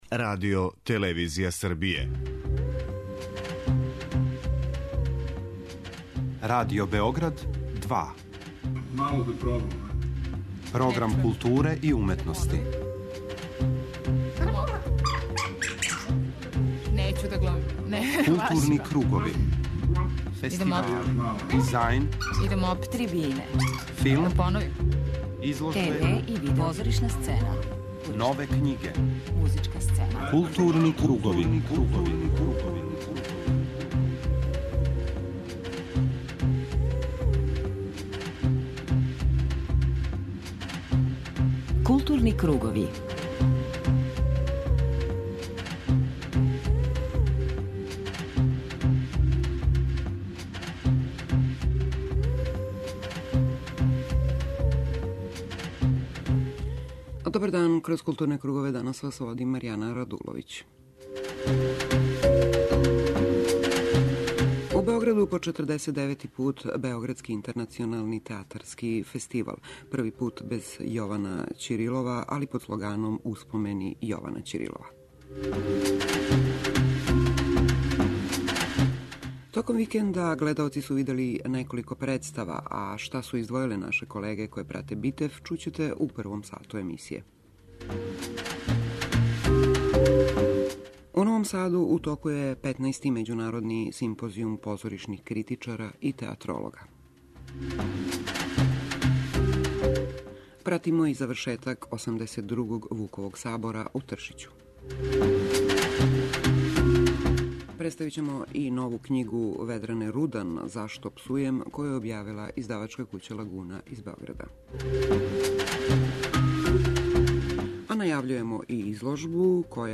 преузми : 52.54 MB Културни кругови Autor: Група аутора Централна културно-уметничка емисија Радио Београда 2.